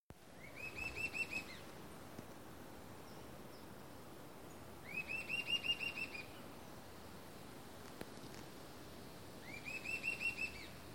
Gritador (Sirystes sibilator)
Classe: Aves
Nome em Inglês: Sibilant Sirystes
Fase da vida: Adulto
Localidade ou área protegida: Parque Provincial Caá Yarí
Condição: Selvagem
Certeza: Observado, Gravado Vocal